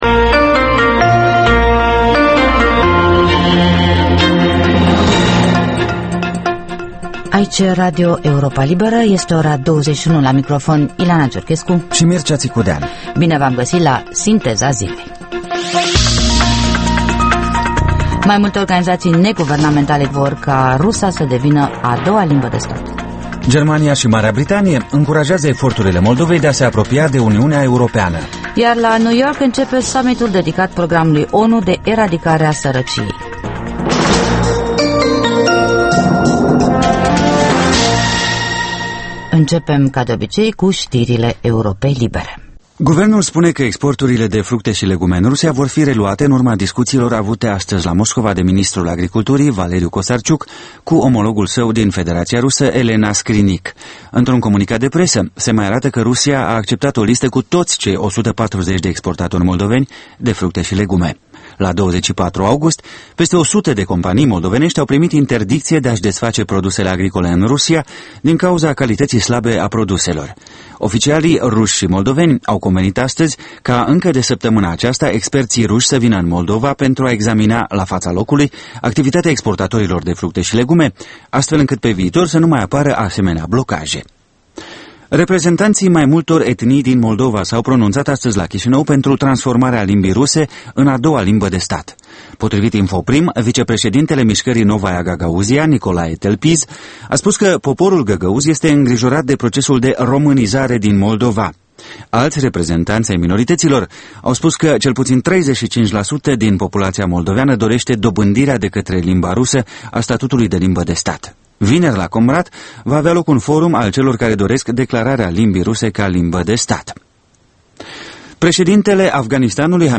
Programul de seară al Europei libere. Ştiri, interviuri, analize şi comentarii.